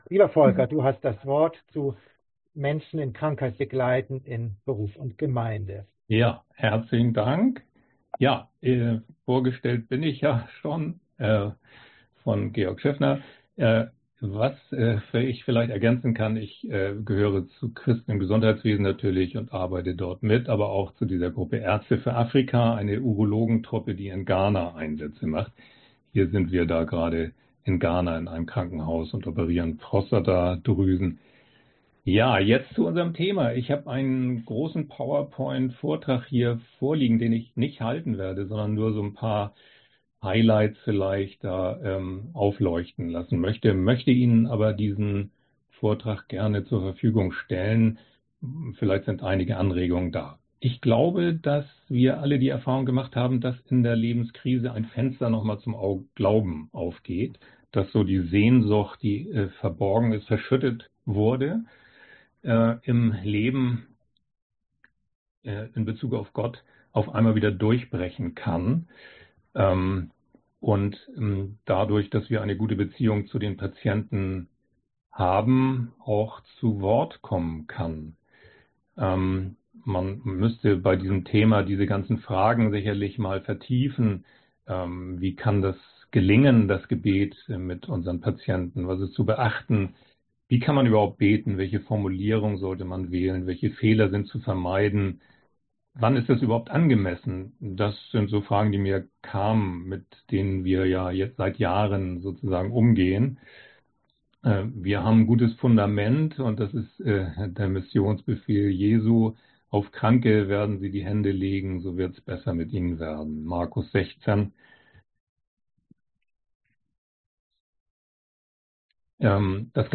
Digitales Kurzseminar